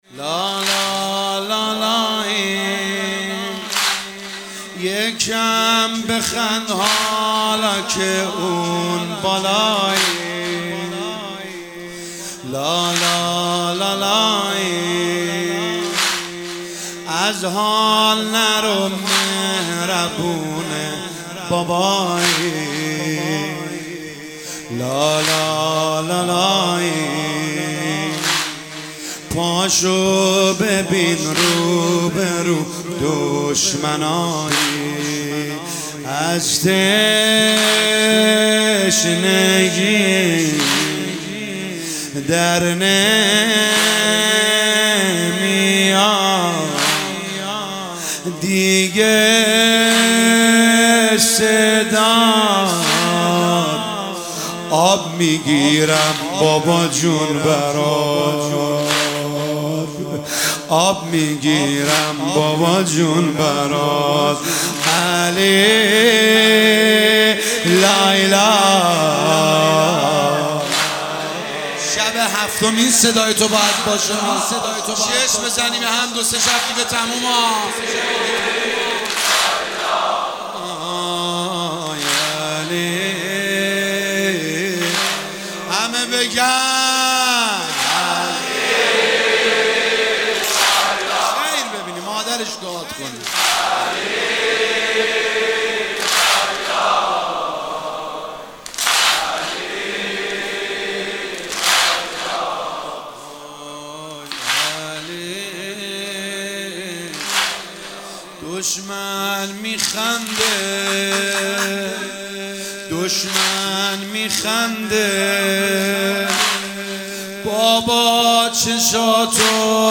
مداحی واحد شب هفتم محرم 1445
هیئت خادم الرضا قم